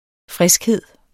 Udtale [ ˈfʁεsgˌheðˀ ]